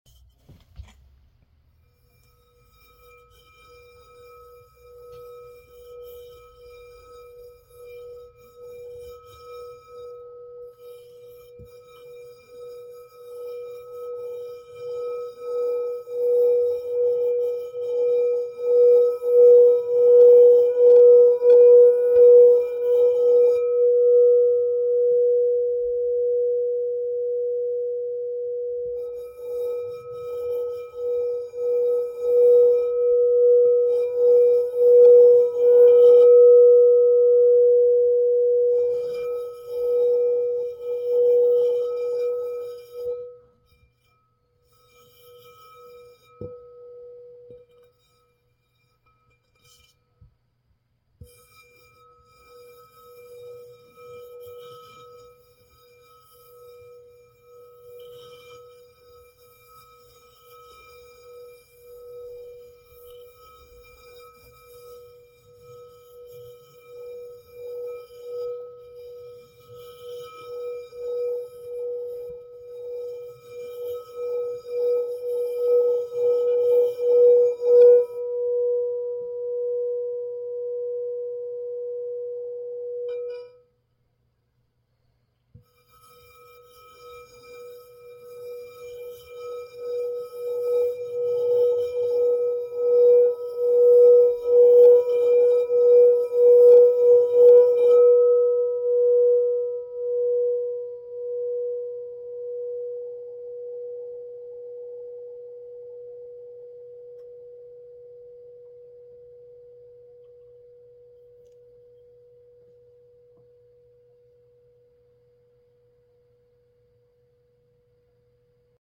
crystal-singing-bowl.mp3